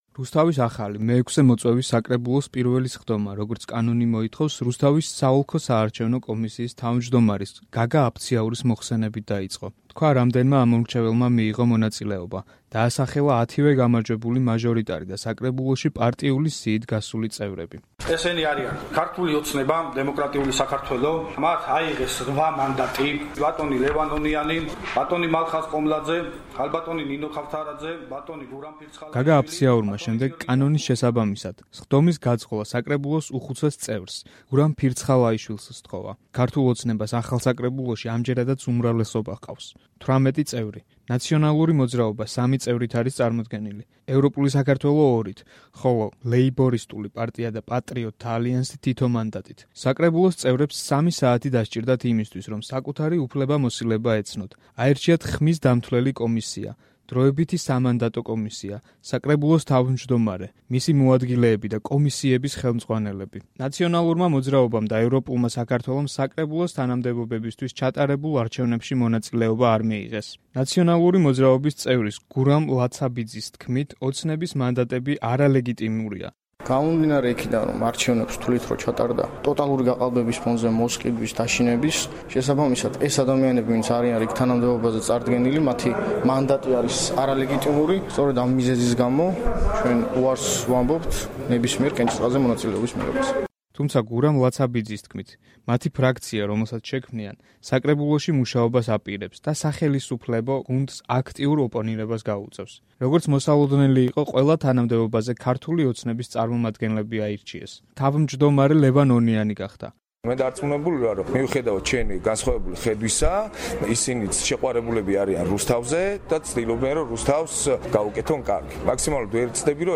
20 ნოემბერს რუსთავში ახალი მოწვევის საკრებულოს პირველი სხდომა გაიმართა. მილოცვებისა და აპლოდისმენტის ფონზე აირჩიეს საკრებულოს თავმჯდომარე, მისი მოადგილე და ქალაქის წარმომადგენლობითი ორგანოს კომისიათა ხელმძღვანელები.